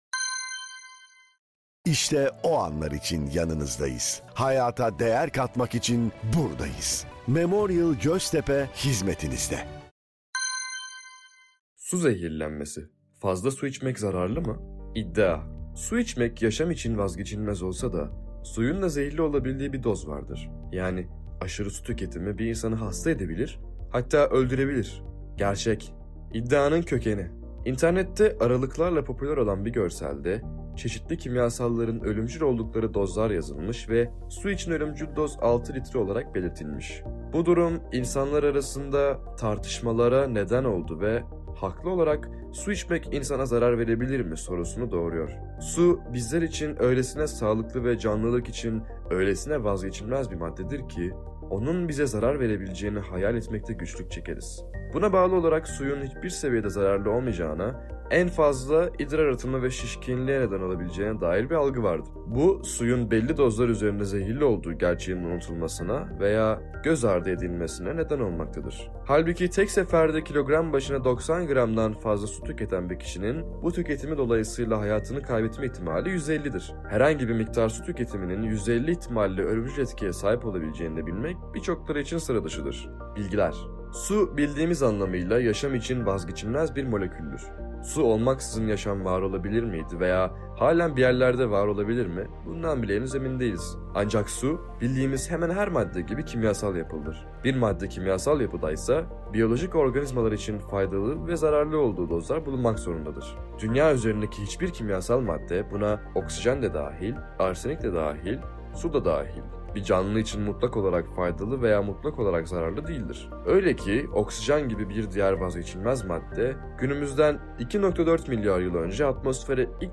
Bu yayın listemizde, Evrim Ağacı'nda yayınladığımız içeriklerden seçilmiş yazılarımızı yazarlarımızın kendileri, diğer yazarlarımız veya ses sanatçıları seslendirerek, sizlerin kulaklarına ulaştırıyor.